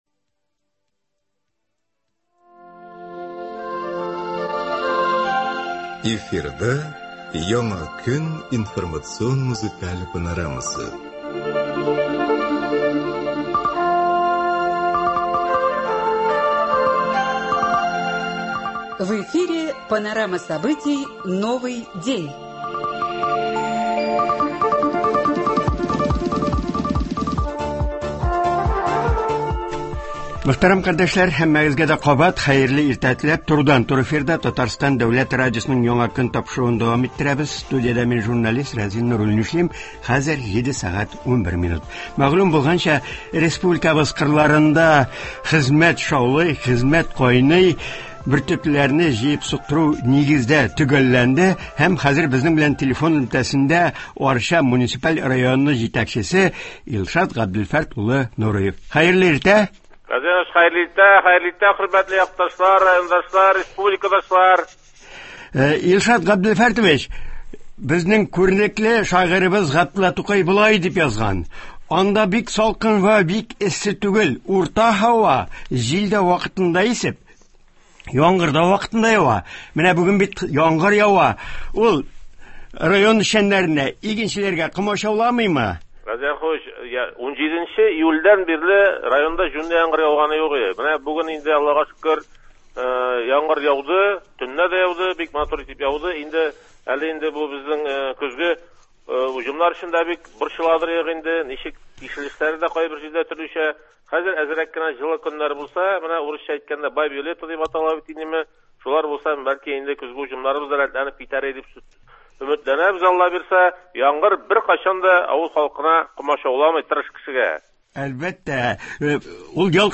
Туры эфир (26.09.22)
Арча муниципаль районы җитәкчесе Илшат Нуриев телефон элемтәсе аша уңышка ирешү серләре турында сөйләячәк.